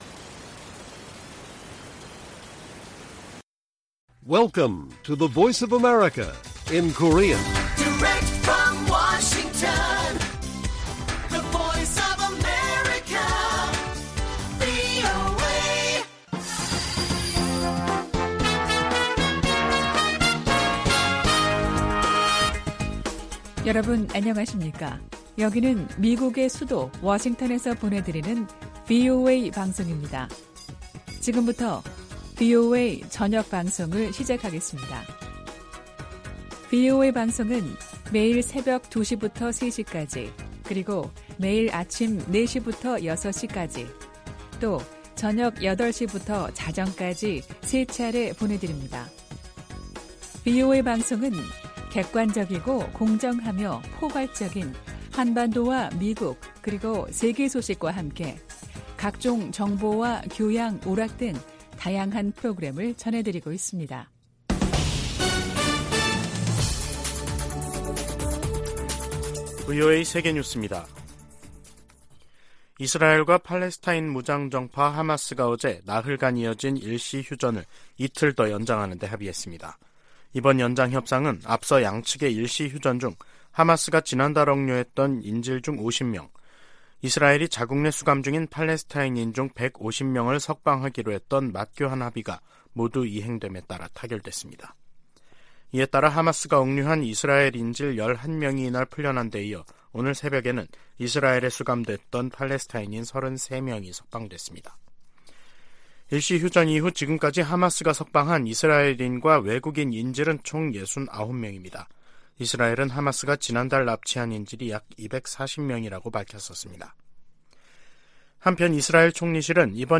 VOA 한국어 간판 뉴스 프로그램 '뉴스 투데이', 2023년 11월 28일 1부 방송입니다. 북한은 군사정찰위성 ‘만리경 1호’가 백악관과 군 기지 등 미국 본토 내 주요 시설을 촬영했다고 주장했습니다.